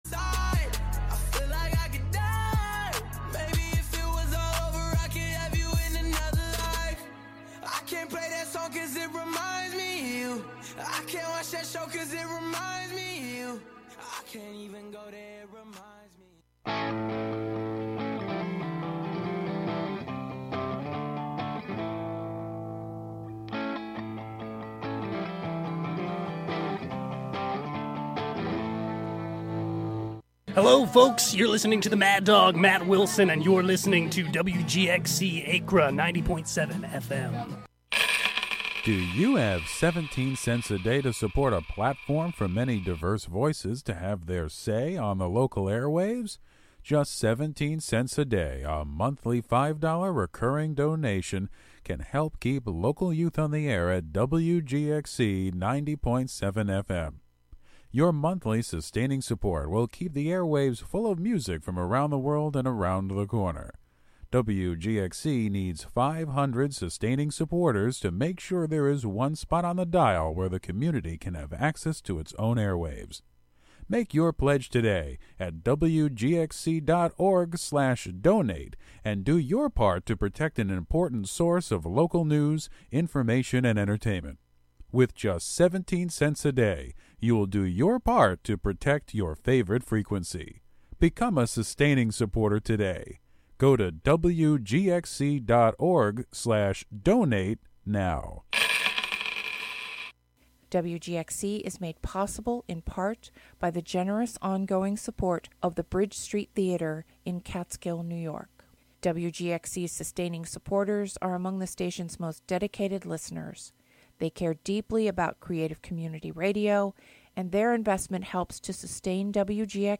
Featuring gospel, inspirational, soul, R&B, country, christian jazz, hip hop, rap, and praise and worship music of our time and yesteryear; interwoven with talk, interviews and spiritual social commentary